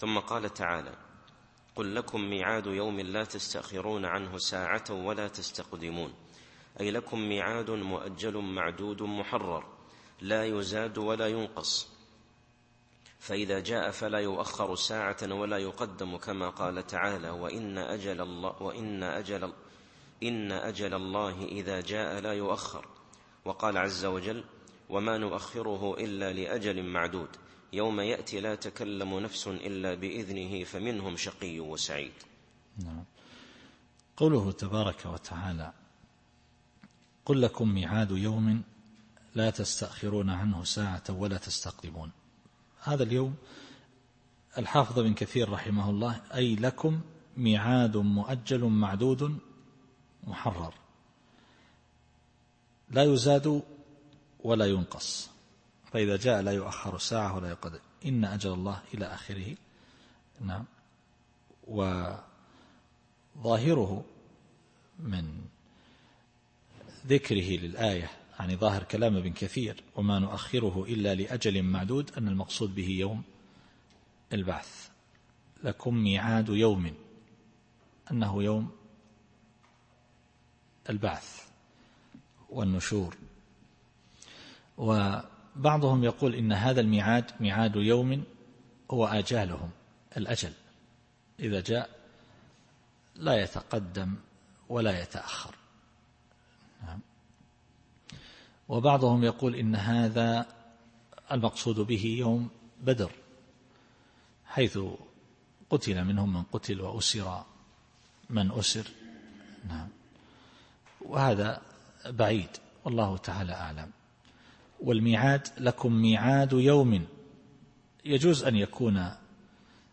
التفسير الصوتي [سبأ / 30]